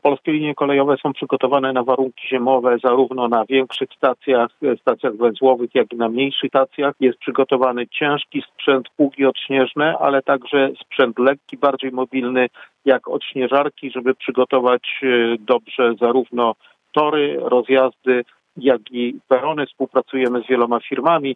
mówi w rozmowie z Radiem Zielona Góra